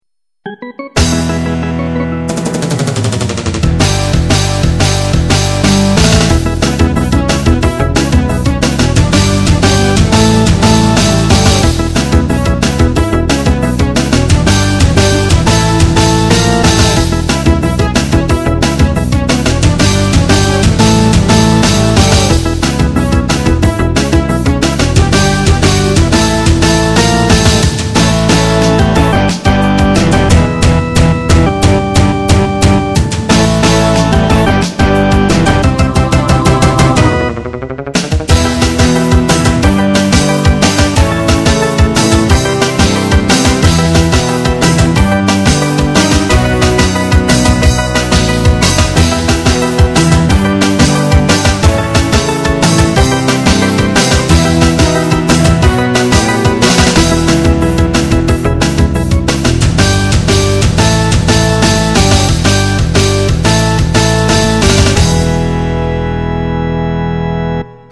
Shining New Day - Conceived at about 4AM after listening to Sakura Saku (OP from Love Hina) and considering other OPs from Psychoshoujo shows I know (mainly "7 O'Clock News," the OP from Kodocha) this song stuck itself in my head and wouldn't get out. Very bouncy and genki; sounds kinda surferish. There's a vocal line somewhere in there, but 1) it's kinda hidden in there and 2) I don't have any lyrics for it, so having one in there is kind of artificial.